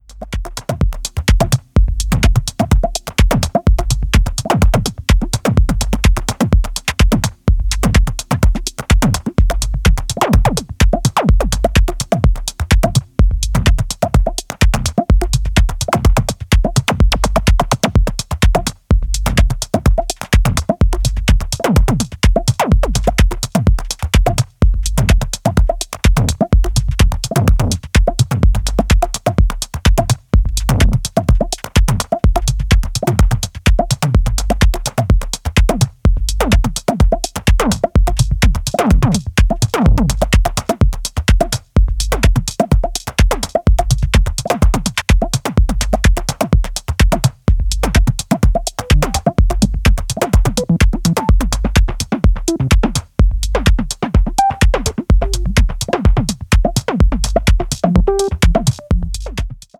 French duo